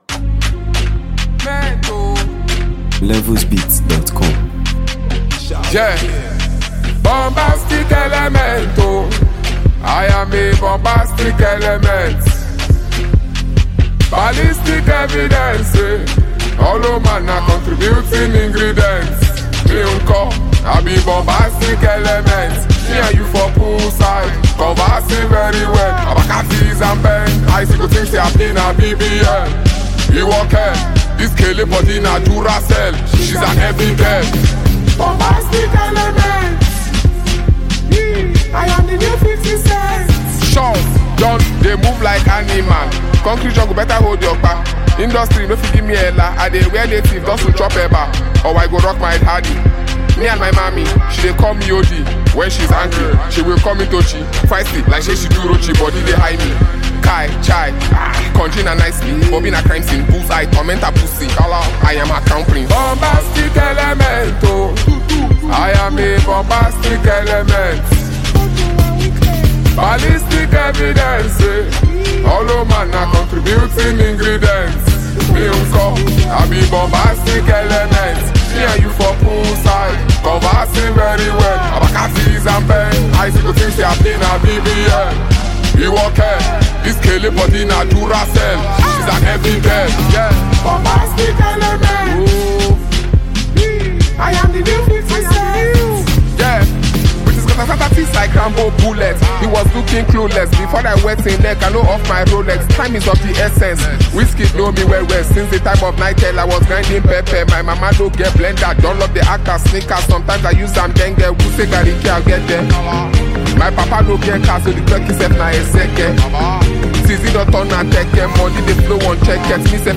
This energetic and lyrically rich record
hip-hop